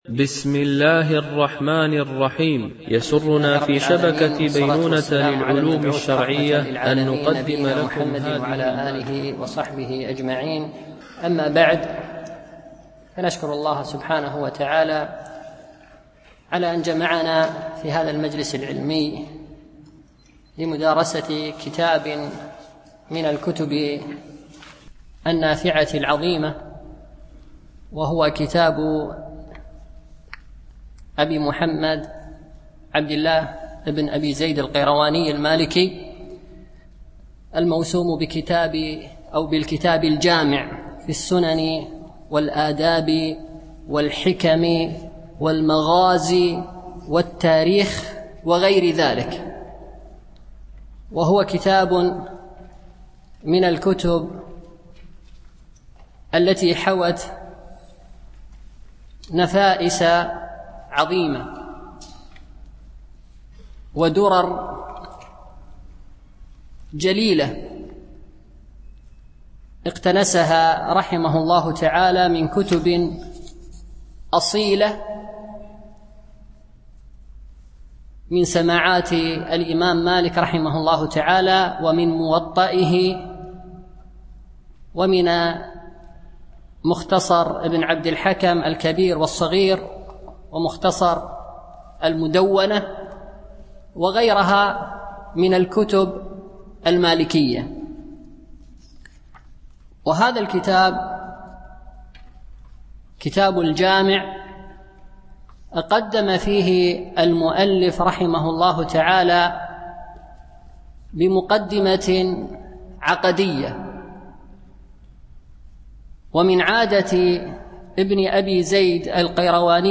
دورة الإمام مالك العلمية الخامسة، بدبي